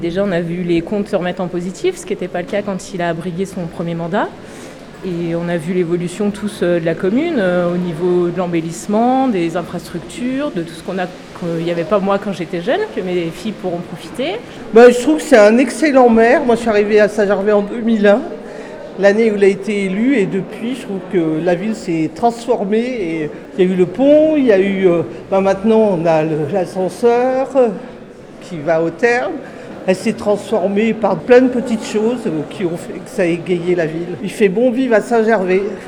ITG Micro trot 1 - Soutien Peillex Saint Gervais (35’’)